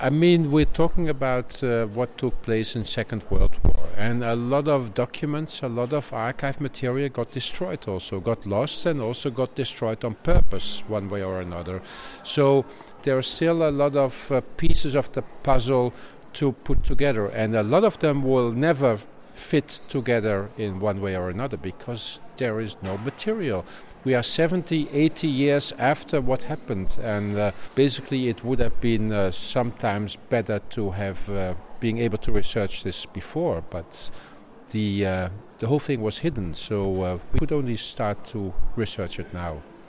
Un interviu în exclusivitate